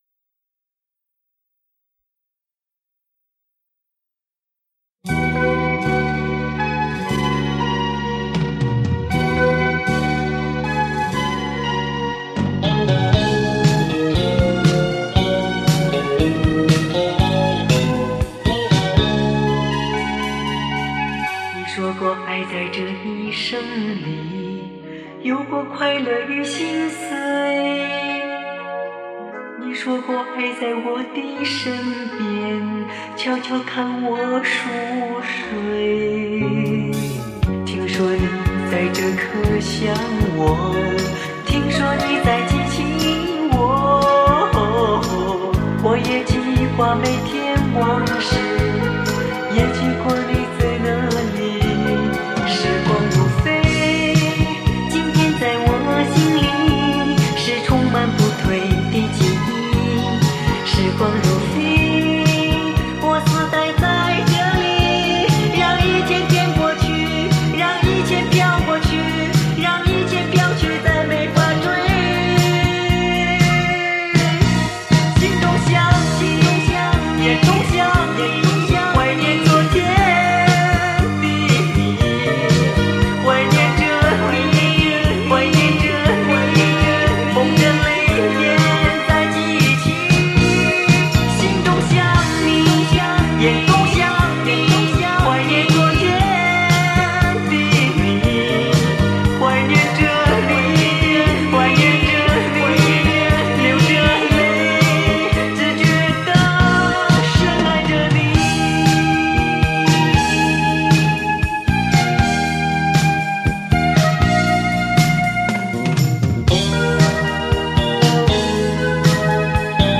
他那款款深情地演唱和那华丽的嗓音，他的快歌很有功底，慢歌则深情款款叫人百听不厌。
磁带数字化